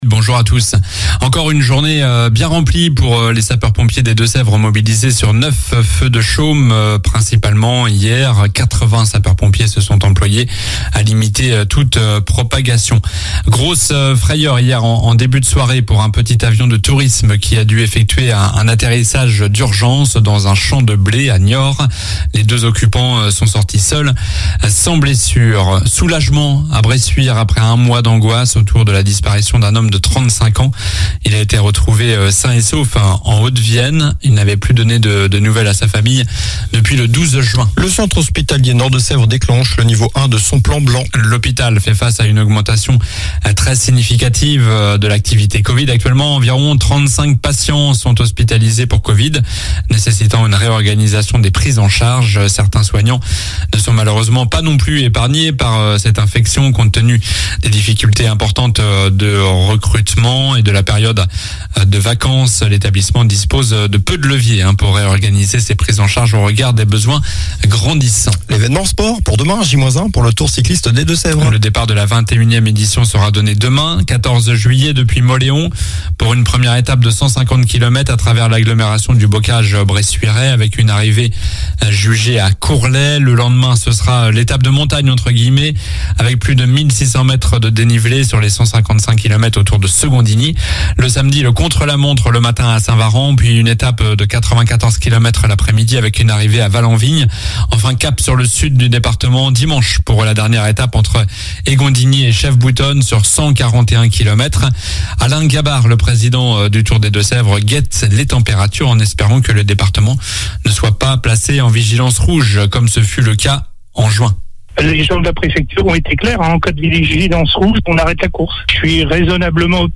Journal du mercredi 13 juillet (matin)